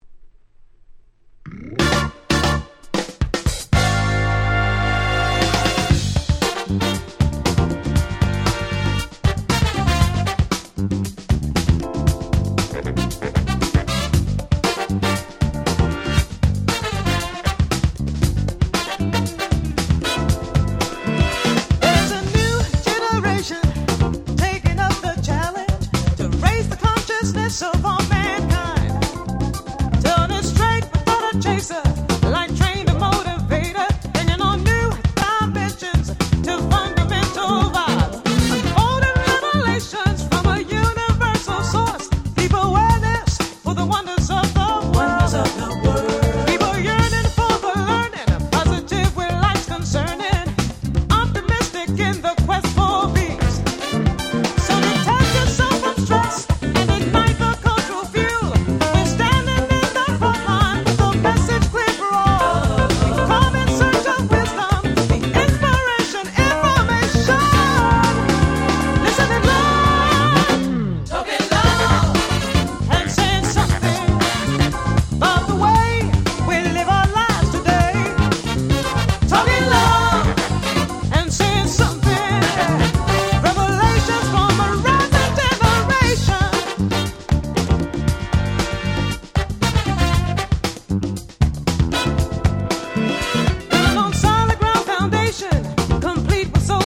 94' Very Nice Acid Jazz / Vocal House !!